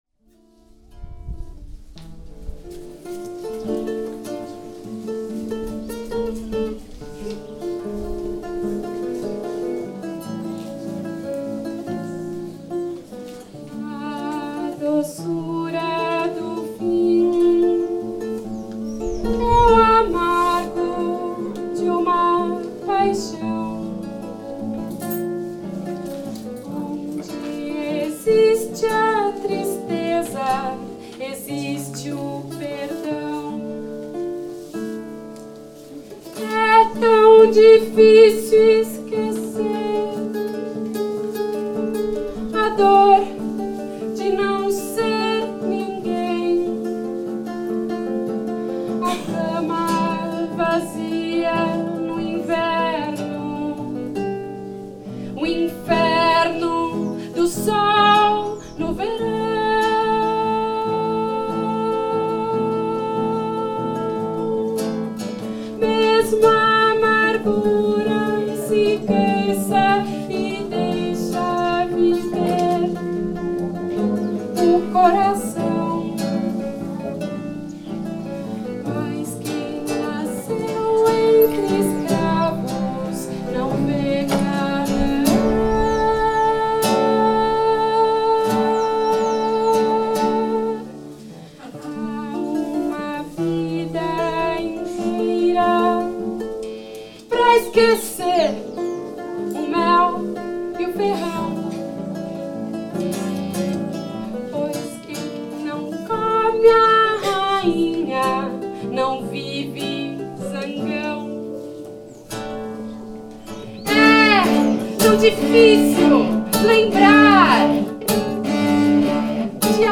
Visão Futuro, Porangaba /